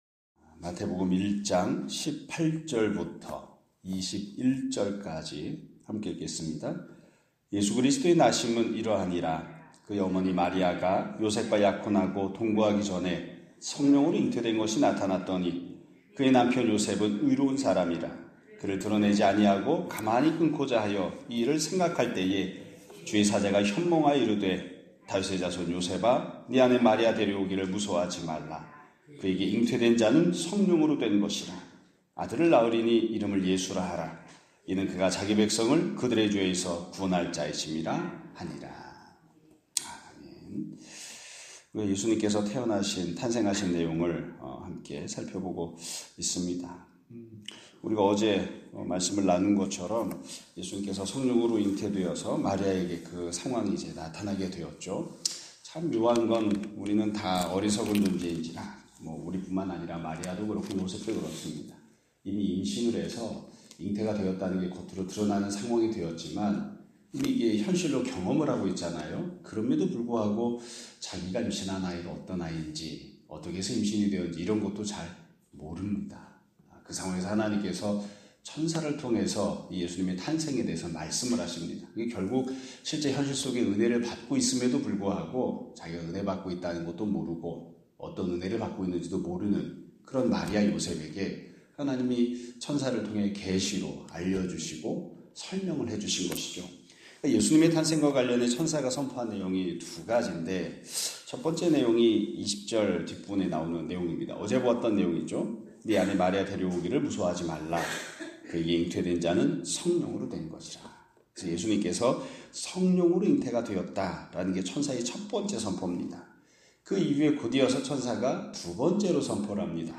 2025년 3월 25일(화요일) <아침예배> 설교입니다.